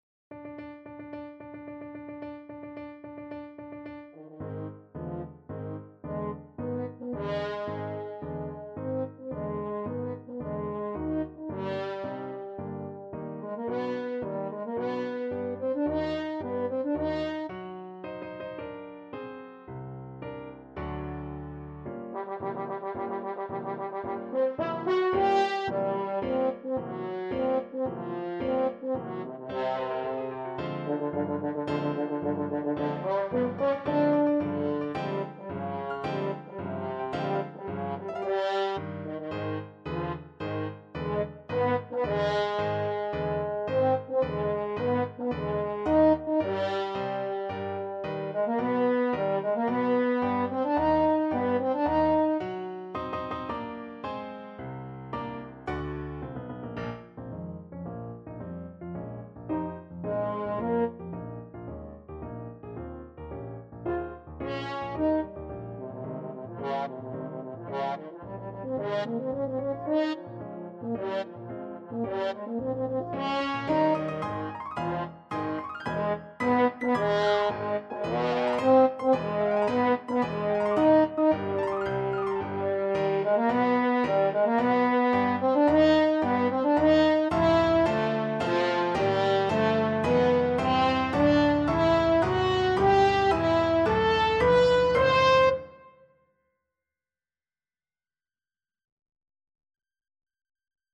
French Horn
~ = 110 Tempo di Marcia
C major (Sounding Pitch) G major (French Horn in F) (View more C major Music for French Horn )
4/4 (View more 4/4 Music)
B3-C6
Classical (View more Classical French Horn Music)